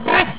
c_rhino_hit1.wav